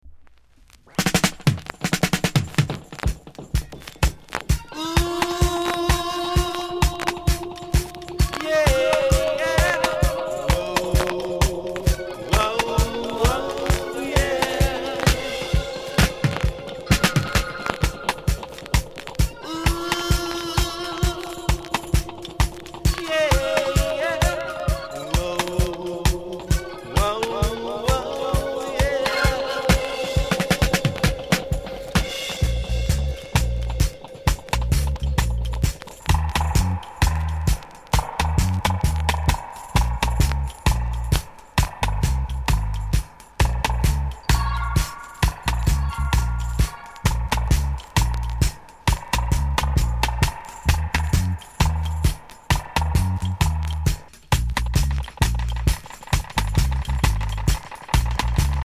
コメント KILLER!!RARE!!※VERSIONサイドの序盤にパチノイズあります。